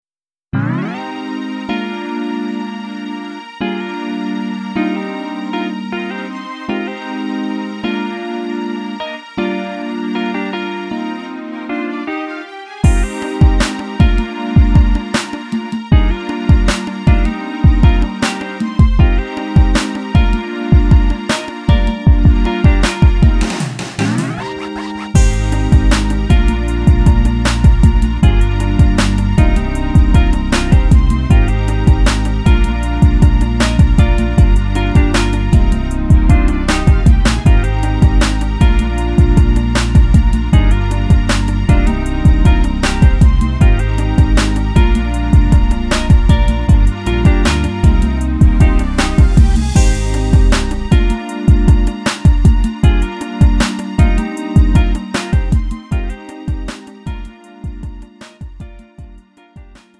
음정 -1키 3:59
장르 구분 Lite MR